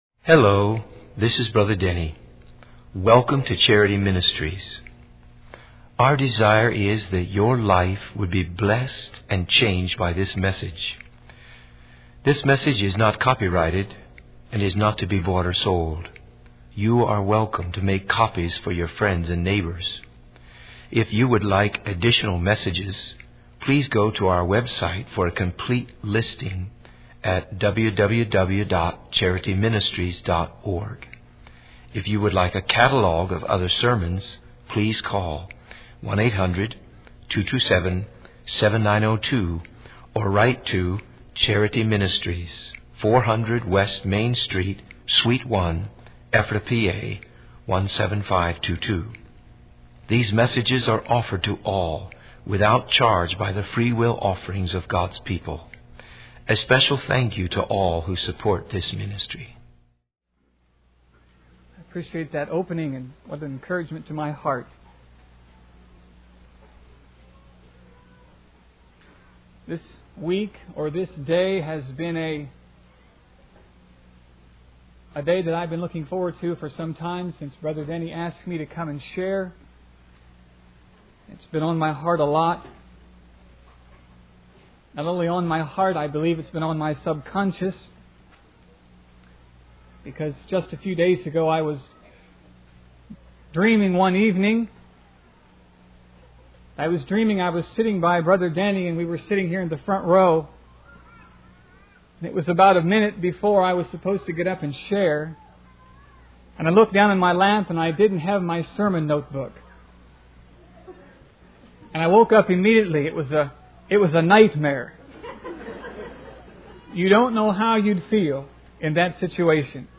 In this sermon, the preacher emphasizes the importance of understanding the sinfulness of mankind in order to grasp the concept of salvation. He uses a story of a bus driver's fatal misjudgment to illustrate the consequences of not recognizing one's own sinfulness. The preacher then references the parable of the Pharisee and the tax collector to highlight the humility and repentance necessary for salvation.